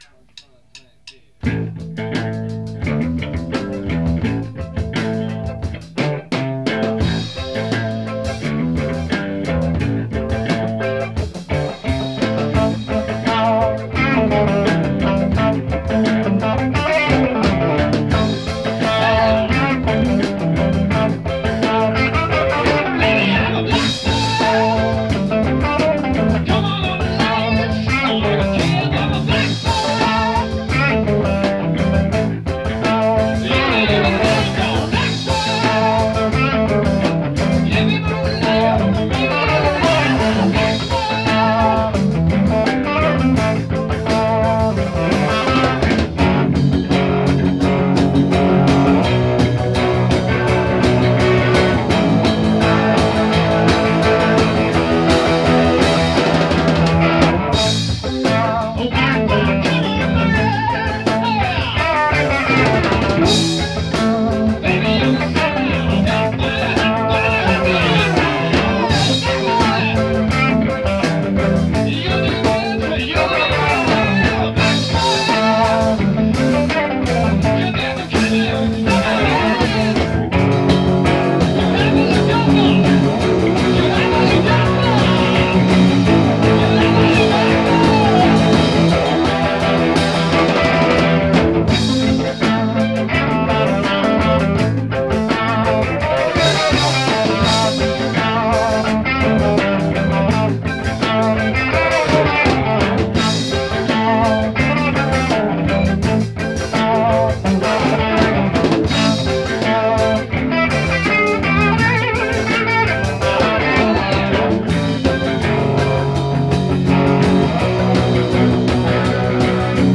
Probelokal 1982